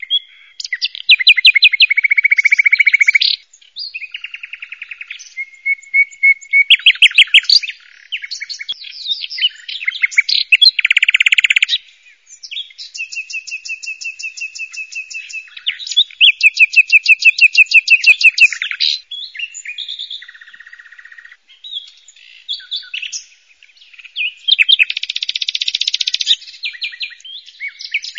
Hayvanlar